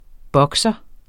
Udtale [ ˈbʌgsʌ ]